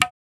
Perc (Genius).wav